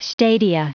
Prononciation du mot stadia en anglais (fichier audio)